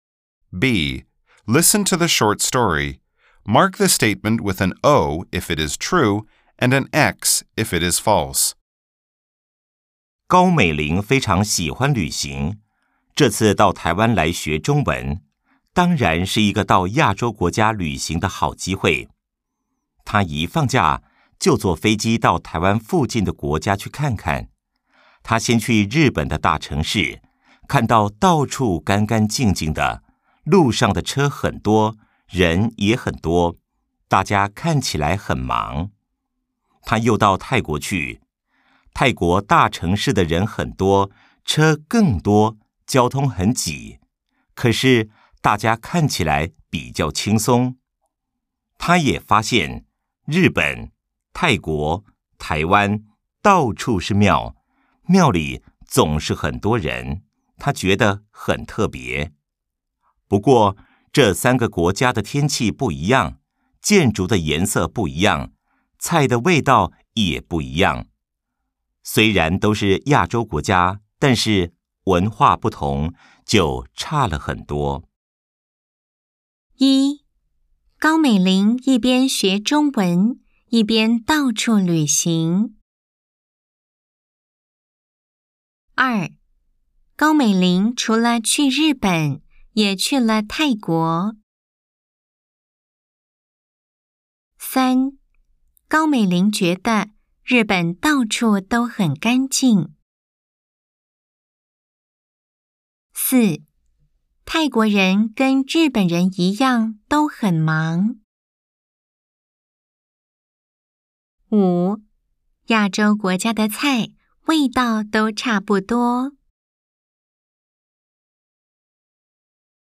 B. Nghe câu chuyện ngắn.